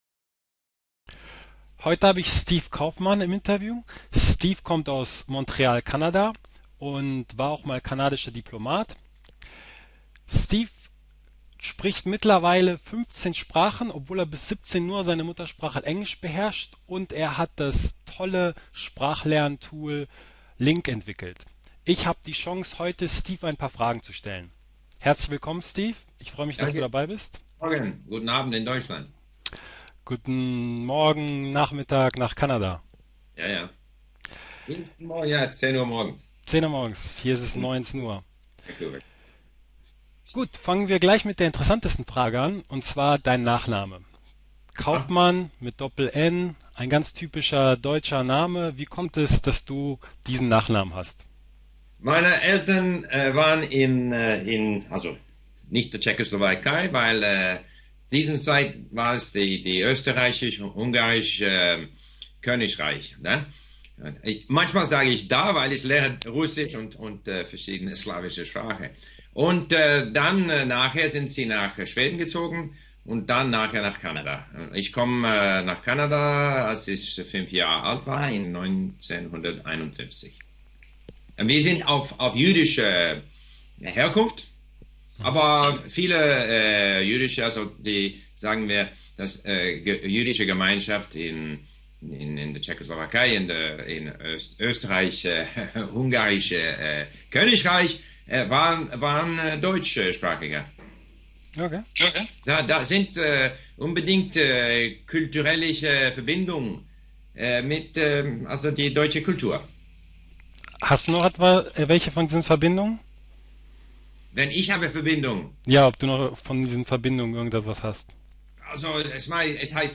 Interview mit dem 15-sprachigen Gründer von LingQ Steve Kaufmann!